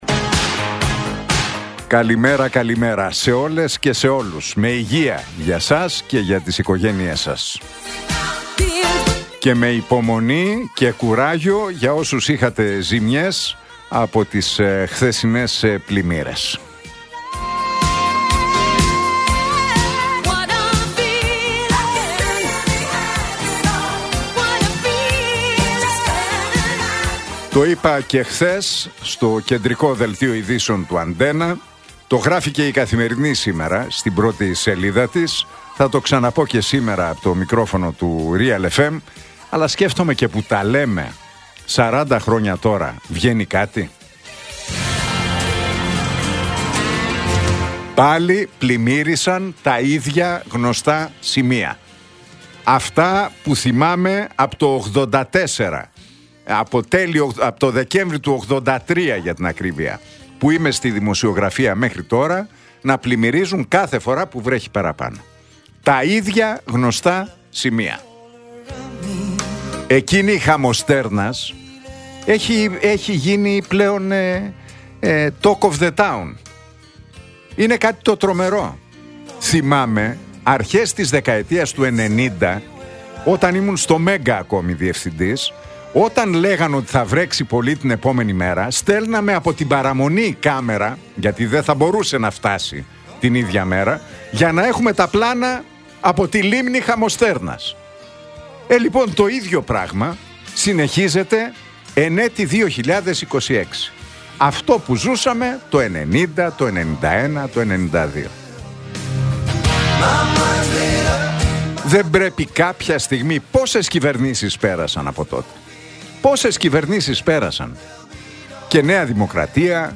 Ακούστε το σχόλιο του Νίκου Χατζηνικολάου στον ραδιοφωνικό σταθμό Realfm 97,8, την Πέμπτη 22 Ιανουαρίου 2026.